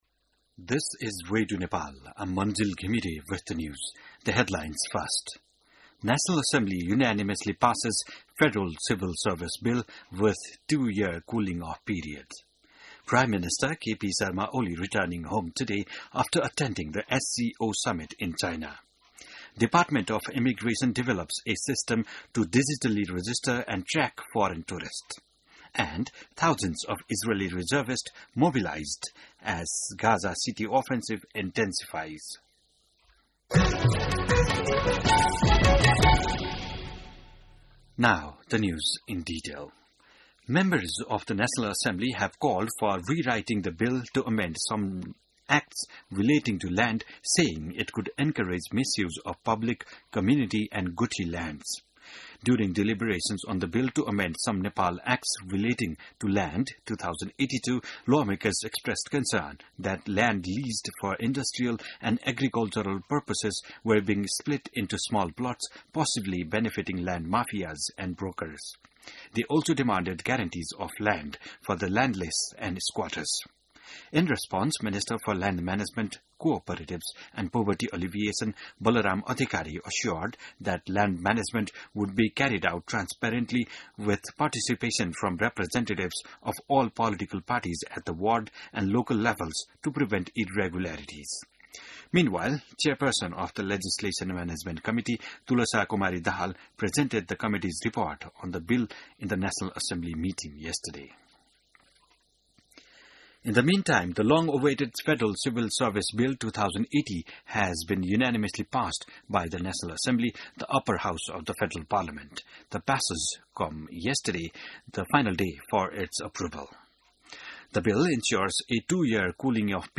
बिहान ८ बजेको अङ्ग्रेजी समाचार : १८ भदौ , २०८२